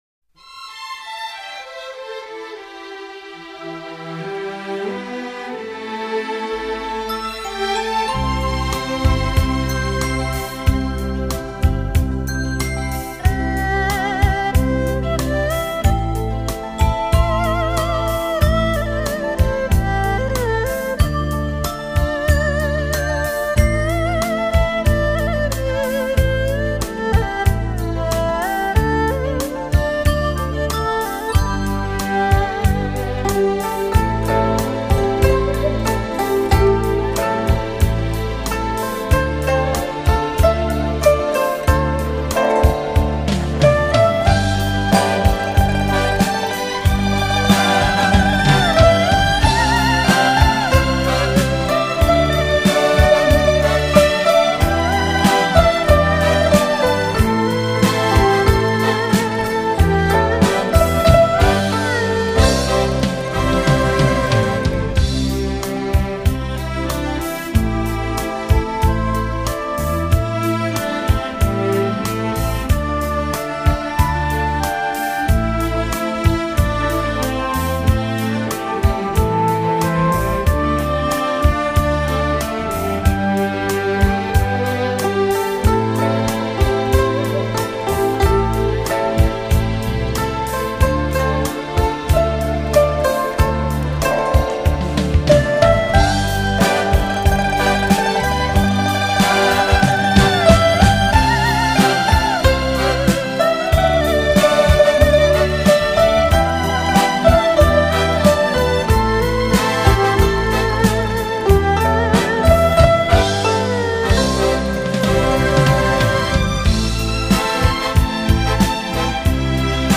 流行音乐交谊舞曲